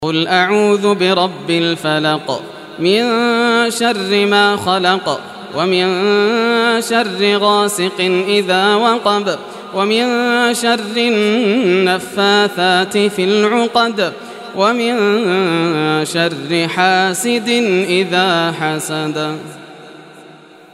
Surah Falaq Recitation by Yasser al Dosari
Surah Falaq, listen or play online mp3 tilawat / recitation in Arabic in the beautiful voice of Sheikh Yasser al Dosari.